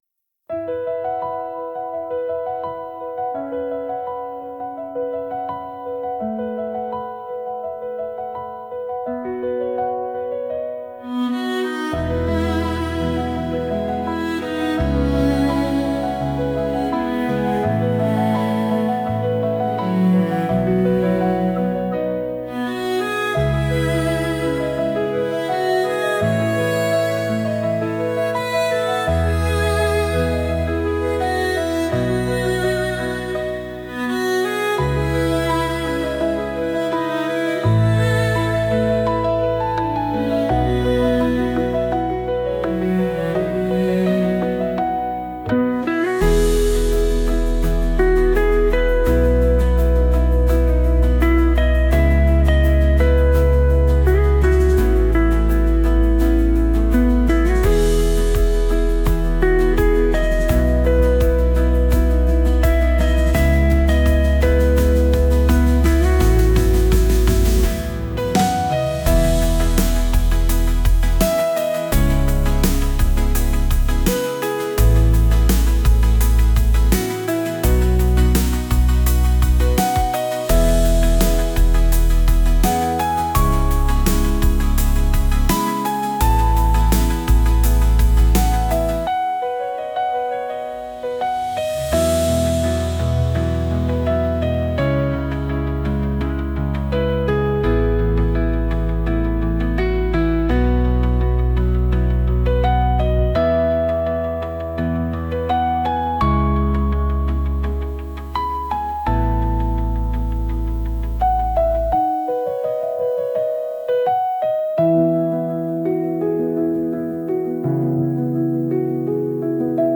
人の愛情に心を打たれたときのBGM